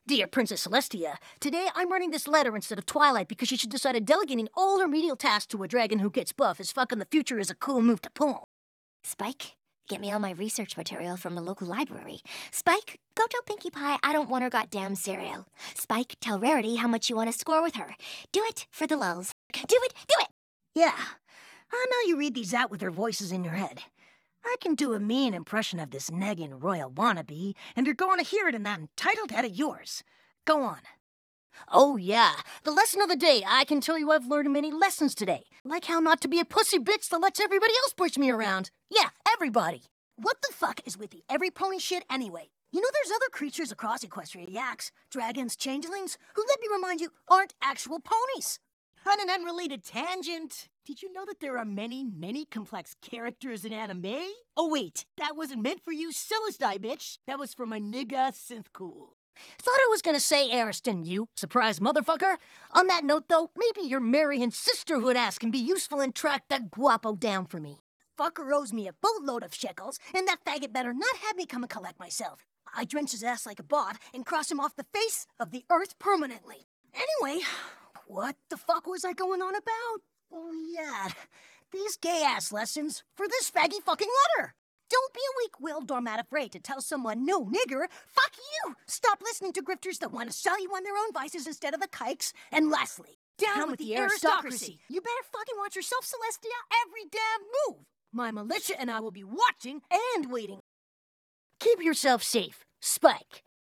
15.ai aided me in creating this absolute masterpiece, and you will all now be subjected to my creation.